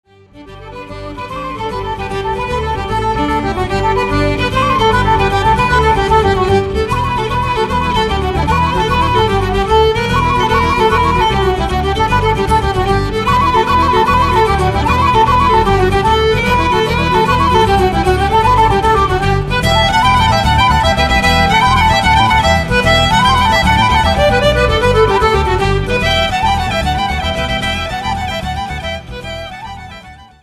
Zespół Forann prezentuje repertuar inspirowany etniczną muzyką ludów celtyckich, a w szczególności muzyką Walii, Szkocji, Bretanii i Irlandii.
skrzypce
gitara basowa
akordeon
flet poprzeczny